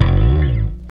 Bass (16).wav